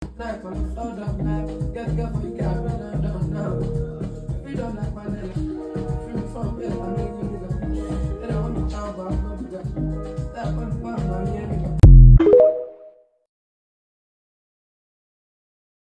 Afrobeat, Amapiano, and street pop
smooth, melodic flows